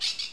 Jungle_Bird_08.wav